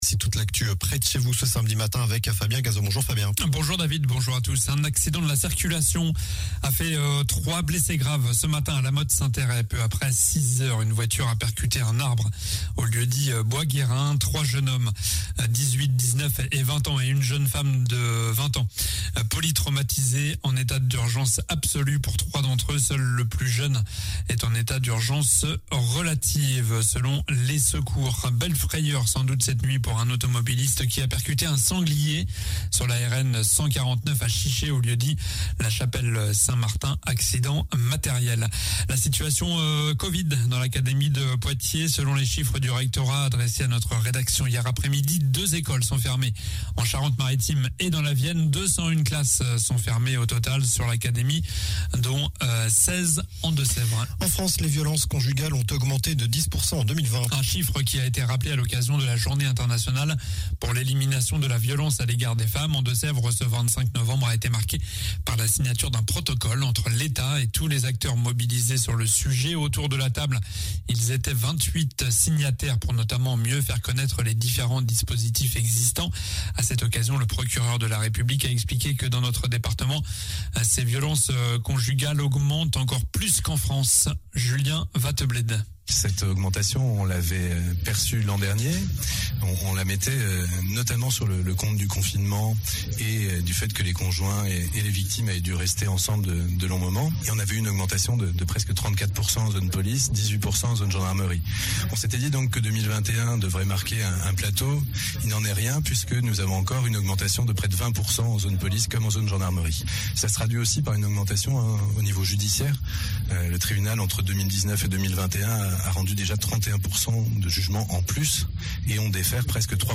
Journal du samedi 27 novembre (matin)